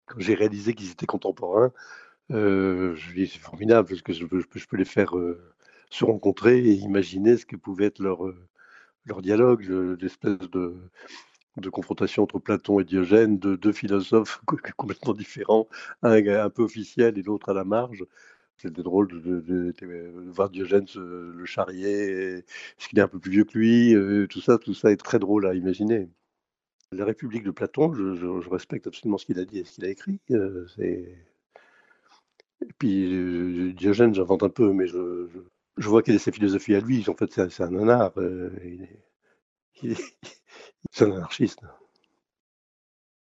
entretien avec Martin Veyron